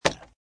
woodice3.mp3